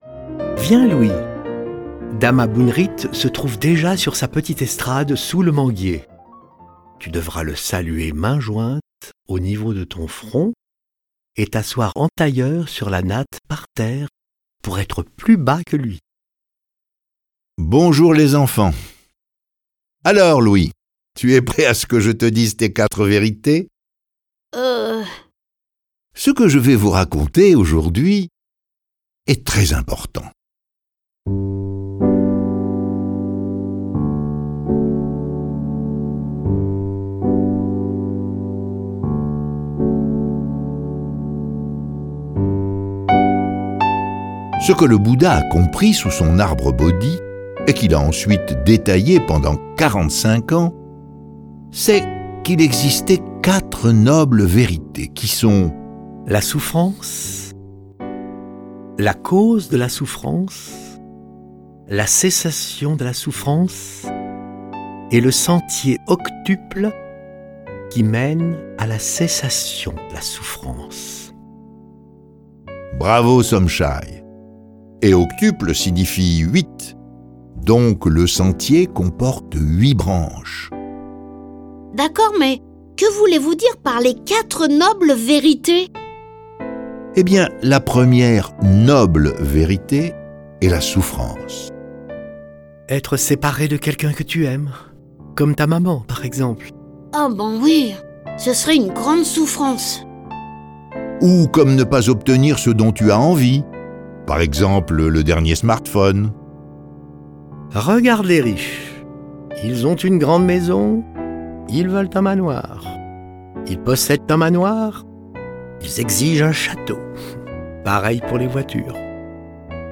Un moine cambodgien leur raconte l’histoire du bouddhisme, né en Inde il y a 2 500 ans, qui enseigne comment vaincre la souffrance et le chagrin et conseille sur la façon de mener sa vie utilement. Ce récit est animé par 8 voix et accompagné de plus de 30 morceaux de musique classique et traditionnelle.